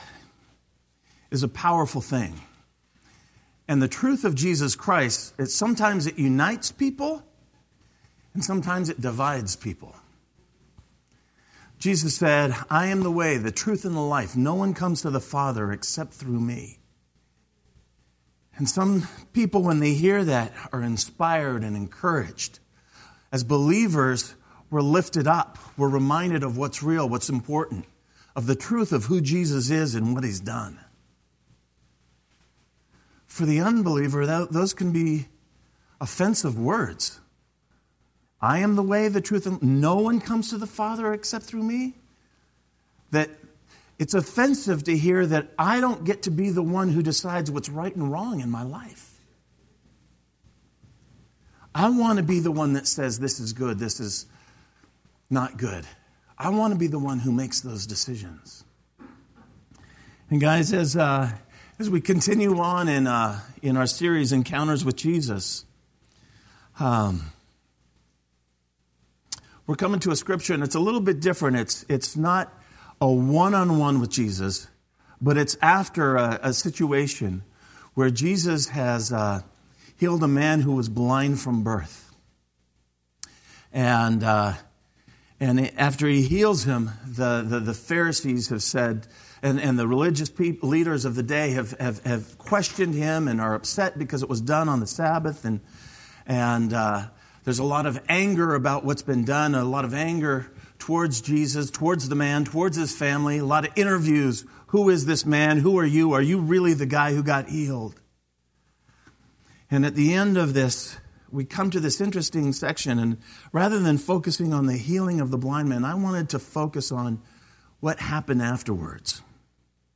Sermon-1-8-17.mp3